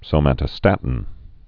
(sō-mătə-stătn, sōmə-tə-)